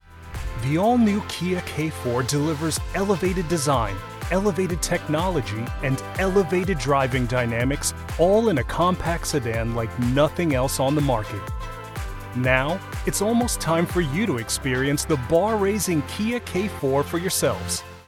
Male
Kia k4 Walkaround Narration
Words that describe my voice are conversational, relatable, genuine.